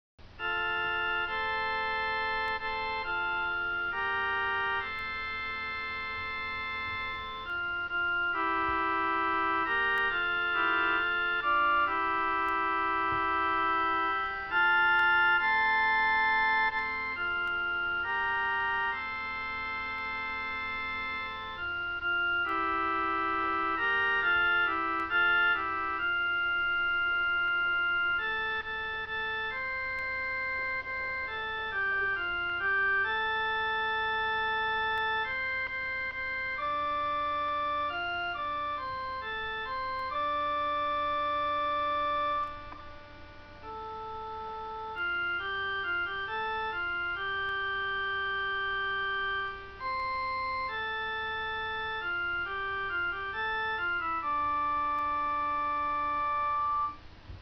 sao chỉ nhạc mà không có lời vậy ?